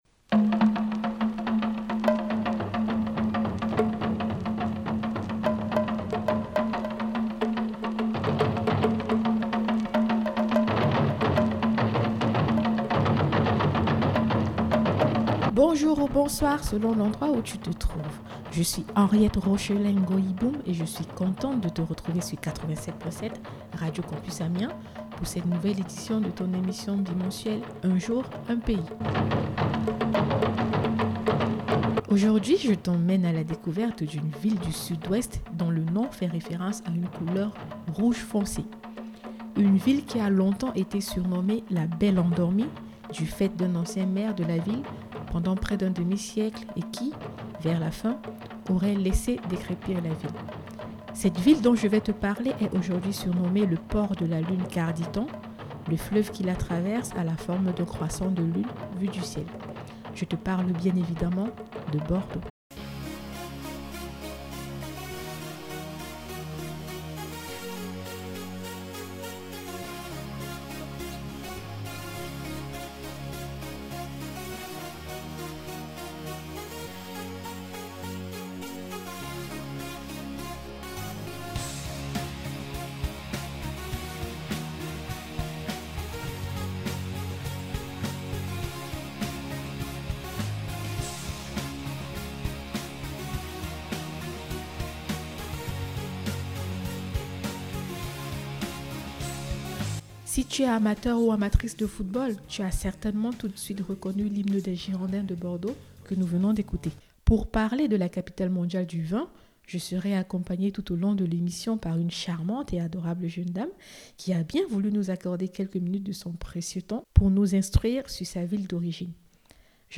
Pour parler de la capitale mondiale du vin, j’ai été accompagnée tout au long de l’émission par une charmante et adorable jeune dame qui a bien voulu m’accorder quelques minutes de son précieux temps pour nous instruire tous sur sa ville d’origine